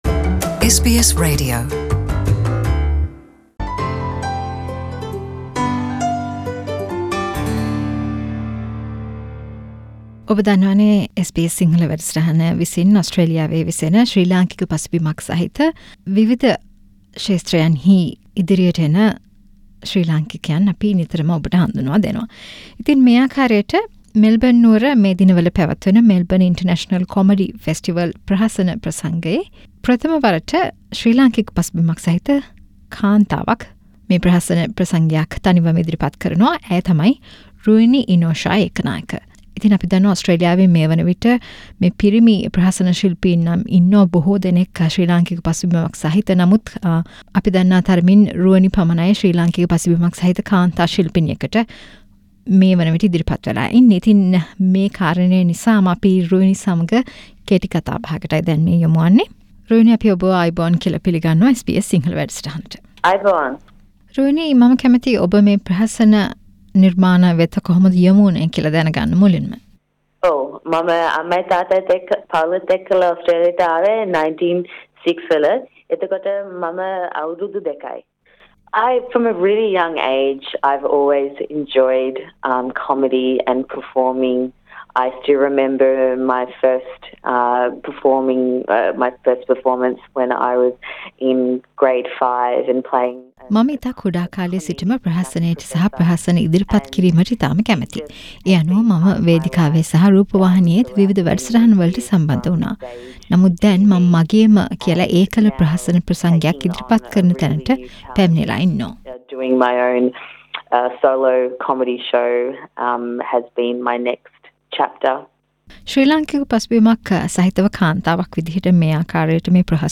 SBS සිංහලසමග කල පිළිසදර